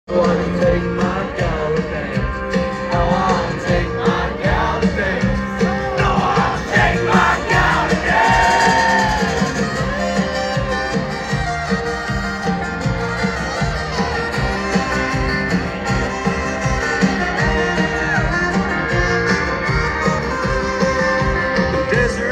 So surreal to be a part of this crowd